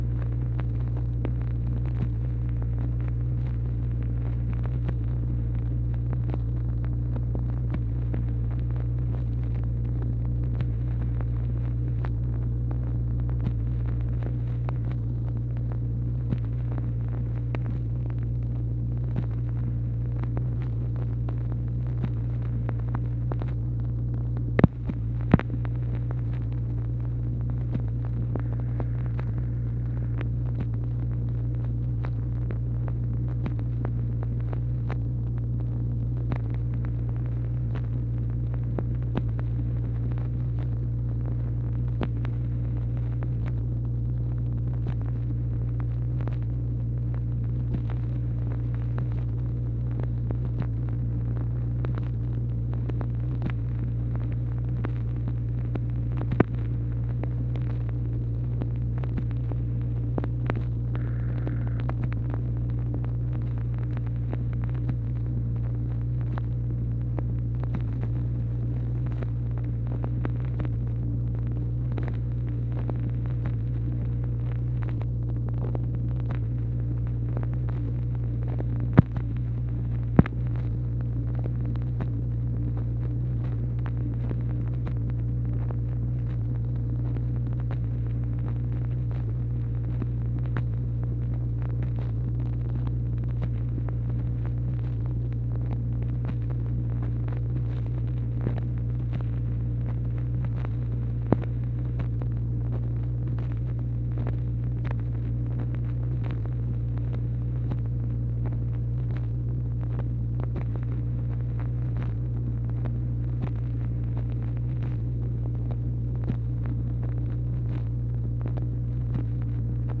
MACHINE NOISE, January 2, 1964
Secret White House Tapes | Lyndon B. Johnson Presidency